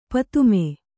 ♪ patumme